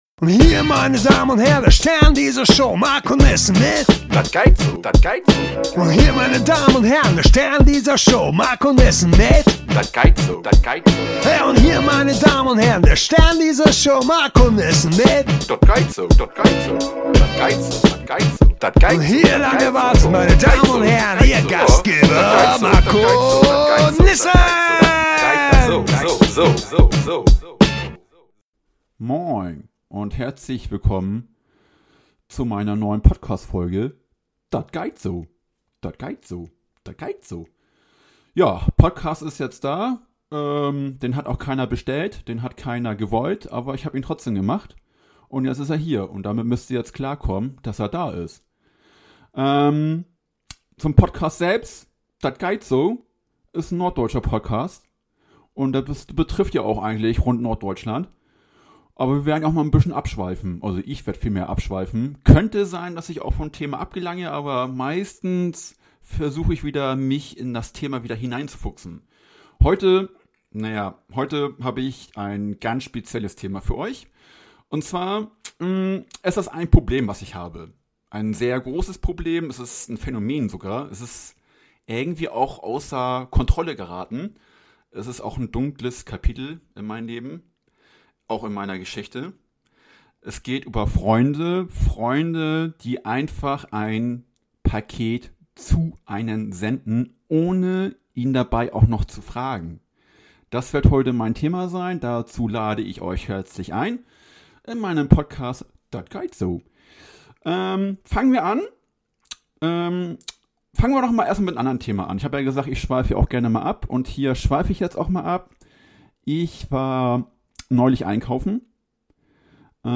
Dat geiht so“ – Dein norddeutscher Podcast mit Humor, Schnack & absurden Geschichten.